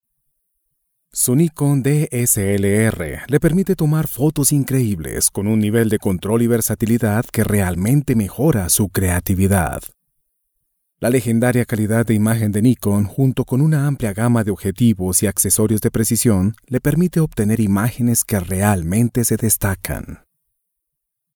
Locutor Colombiano, entregando una voz que interpreta pasión, ternura, seguridad, respaldo.. lo que usted esta buscando.
kolumbianisch
Sprechprobe: eLearning (Muttersprache):